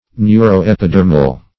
Search Result for " neuro-epidermal" : The Collaborative International Dictionary of English v.0.48: Neuro-epidermal \Neu`ro-ep`i*der"mal\, a. [Neuro- + epidermal.]
neuro-epidermal.mp3